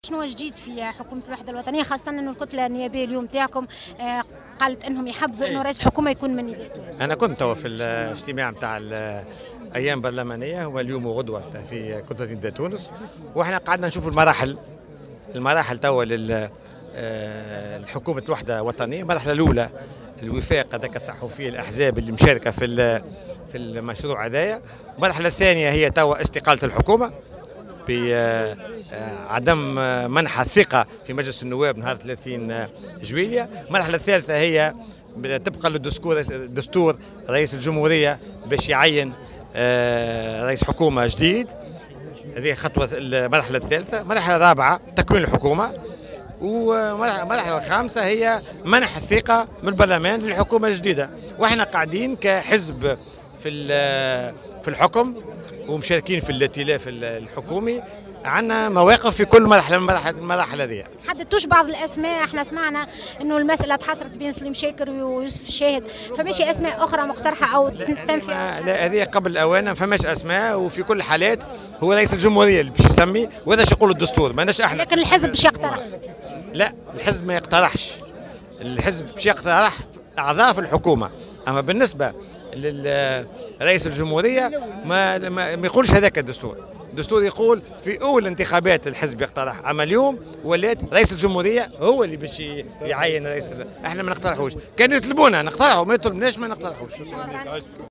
وأكد في تصريحات صحفية على هامش حضوره المؤتمر تأسيسي لحزب مشروع تونس، أن ليس هناك أسماء مقترحة بشان رئيس الحكومة من طرف الأحزاب الرئيسية وأن رئيس الجمهورية هو من يعين رئيس الحكومة فيما يقتصر دور الأحزاب على اقتراح أعضاء الحكومة الجديدة. وأوضح أن هناك 5 مراحل لتشكيل هذه الحكومة وتتمثل في: مرحلة الوفاق ومرحلة استقالة الحكومة ومرحلة تعيين رئيس الحكومة ومرحلة تشكيل الحكومة ومرحلة منحها ثقة البرلمان.